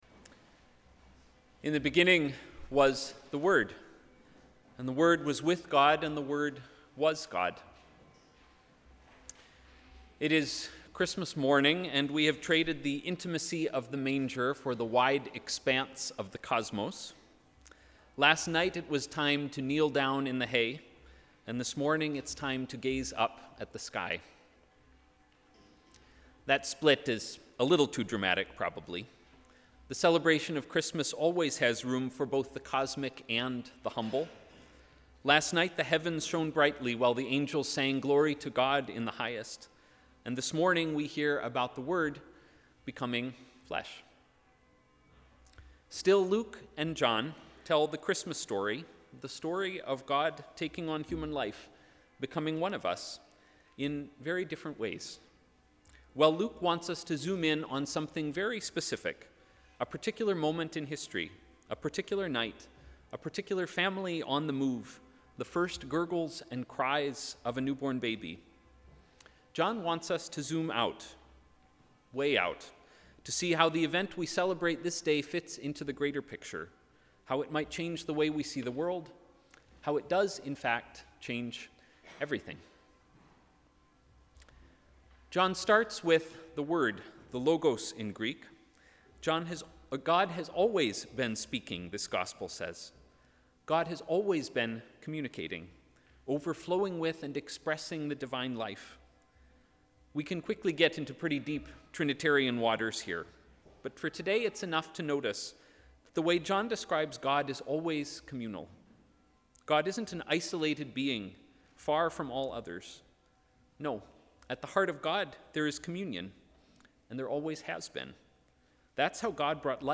Sermon: ‘Embracing our shared humanity’
SermonChristmasDAY.mp3